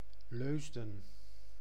Leusden (Dutch pronunciation: [ˈløːzdə(n)]
Nl-Leusden.ogg.mp3